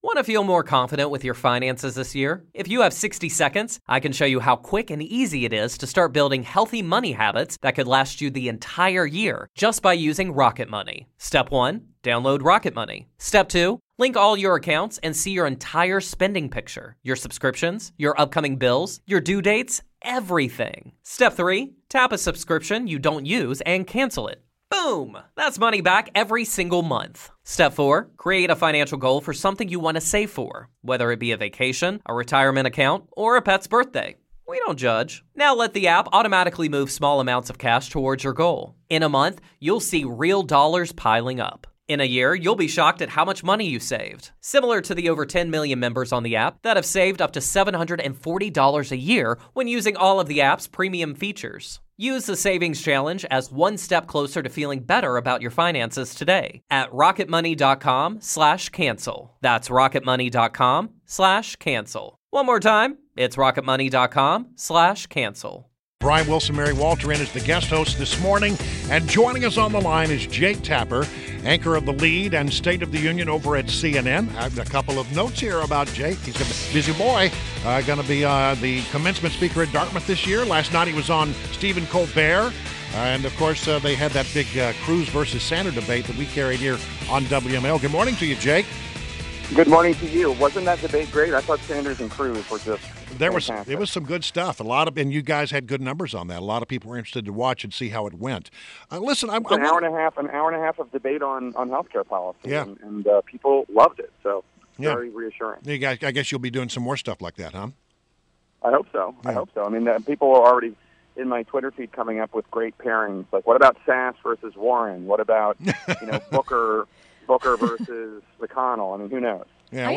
INTERVIEW — JAKE TAPPER – Anchor of THE LEAD and STATE OF THE UNION on CNN